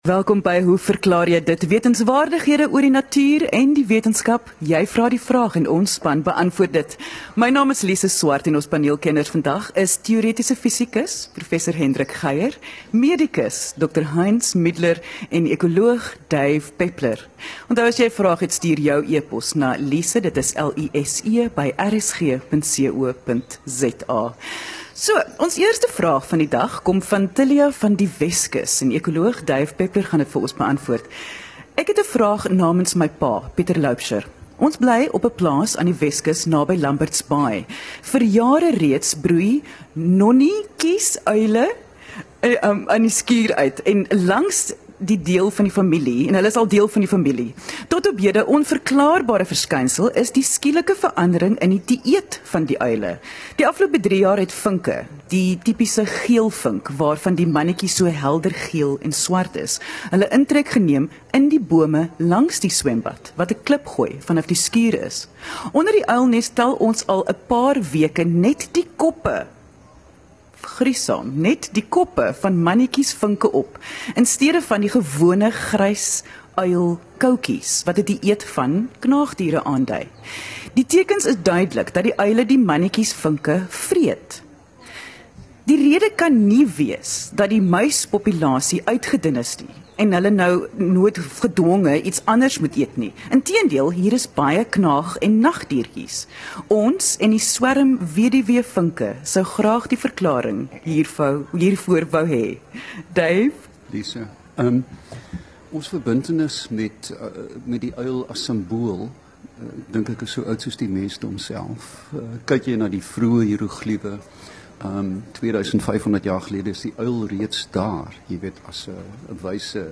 Regstreeks vanaf die Woordfees in Stellenbosch